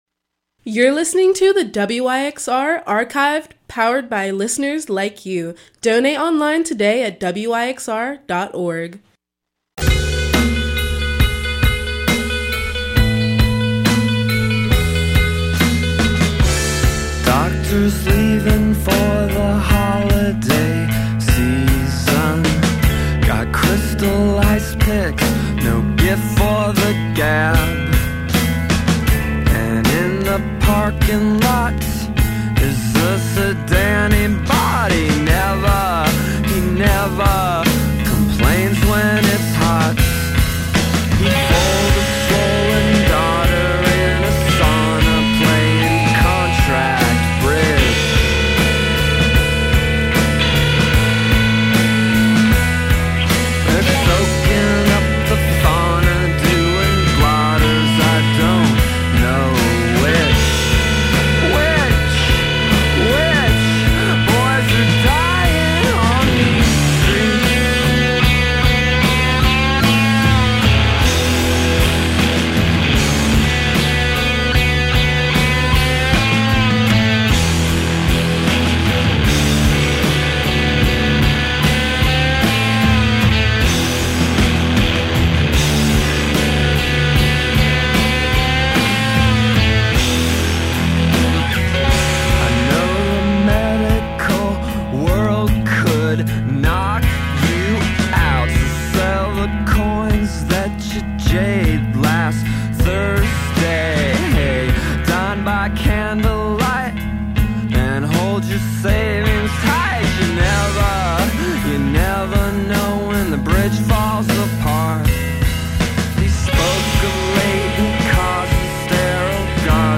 Rock Folk Alternative Indie